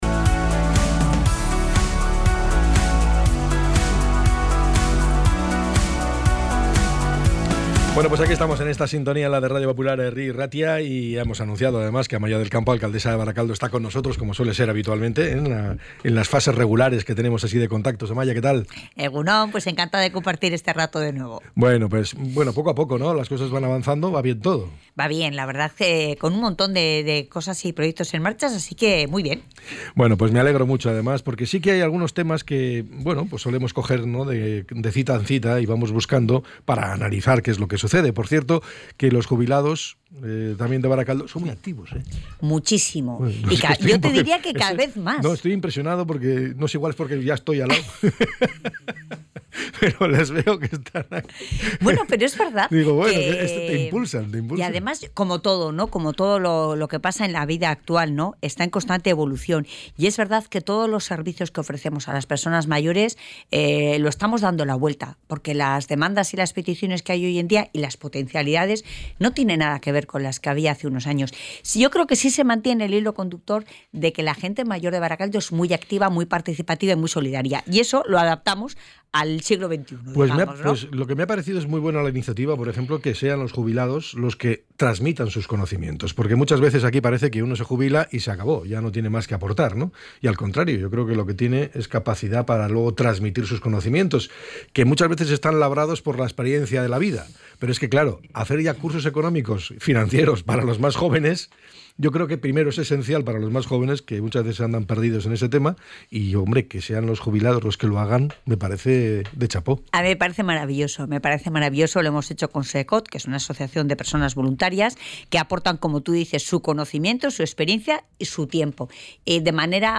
ENTREV.-AMAIA-DEL-CAMPO-11-11.mp3